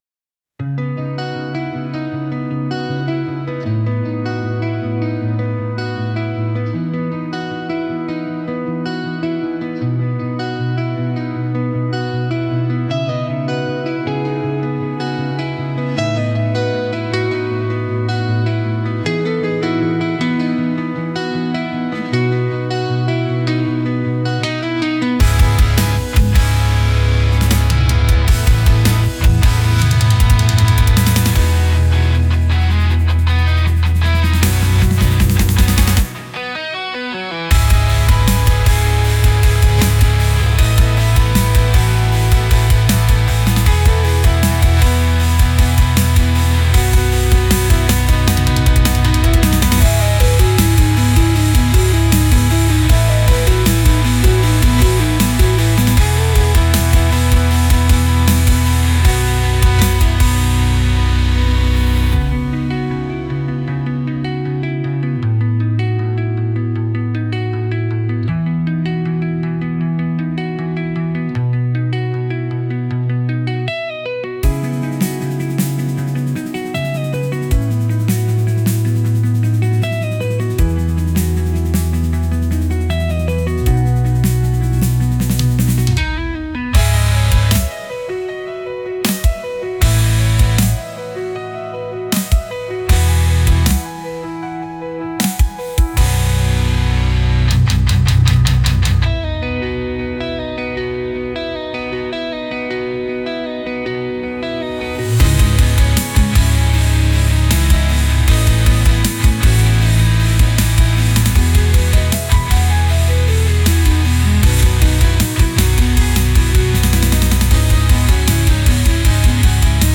Genre: Melancholic Mood: Electronic Editor's Choice